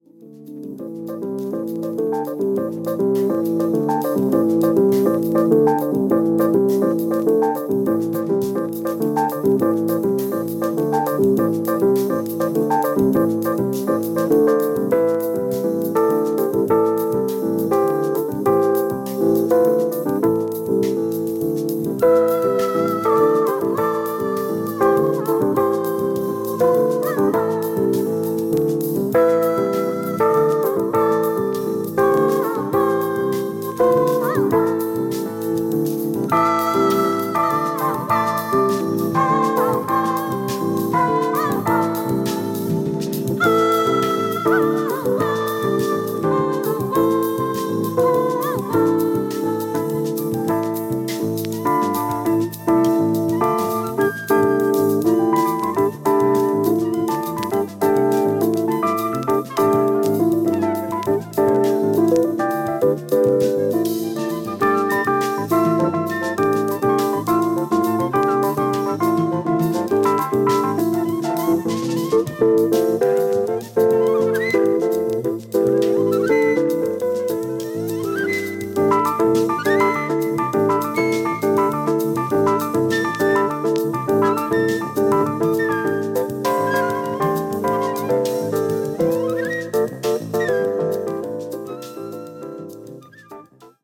Electric Piano
Drums, Percussion
Double Bass
Saxophone